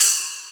VEC3 Cymbals Ride 19.wav